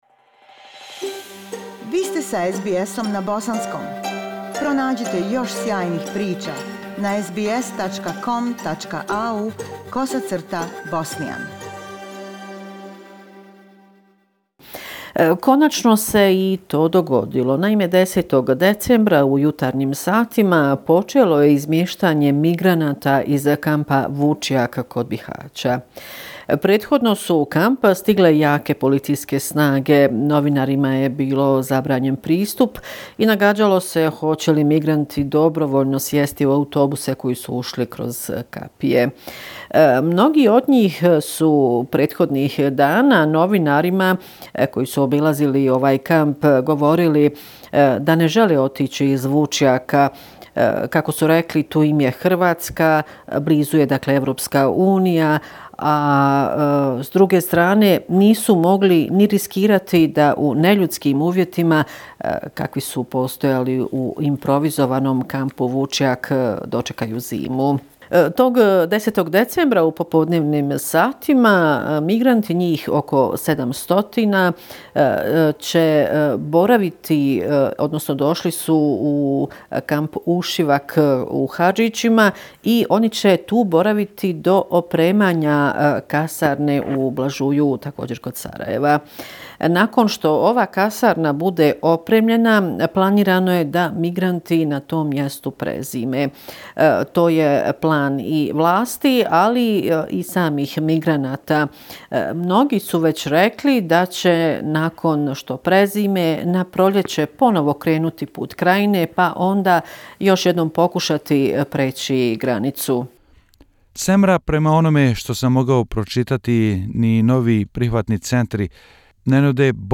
Bosnia and Herzegovina - affairs in the country for the last seven day, weekly report December 15, 2019